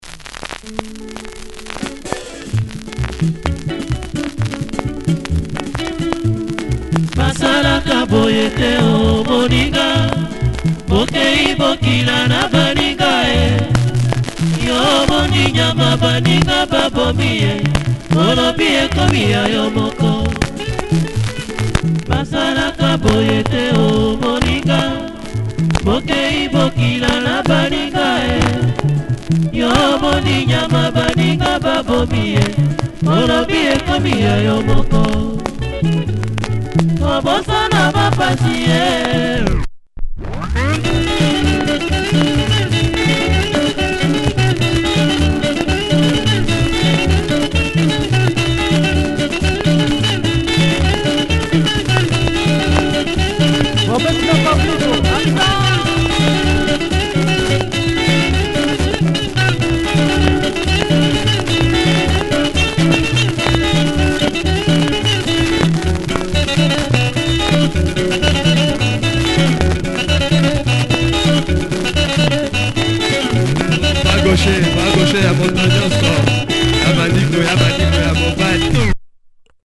Nice Lingala track. Plays with noise.